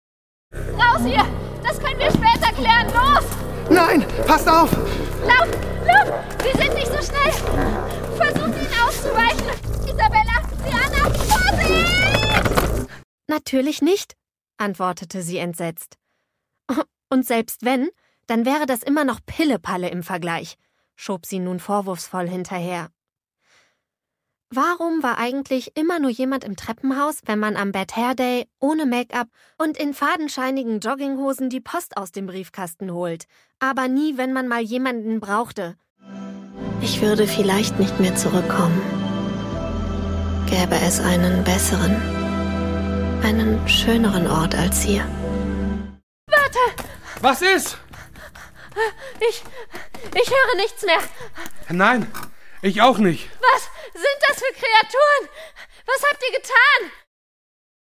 Female
Bright, Character, Cheeky, Confident, Cool, Corporate, Friendly, Natural, Soft, Warm, Versatile, Young, Approachable, Assured, Authoritative, Bubbly, Conversational, Energetic, Engaging, Funny, Posh, Reassuring, Sarcastic, Smooth, Streetwise, Upbeat
My fresh and young, yet warm voice is often described as special and trustworthy at the same time.
REEL AUDIOBOOK AUDIOPLAY DE.mp3
Microphone: Neumann TLM49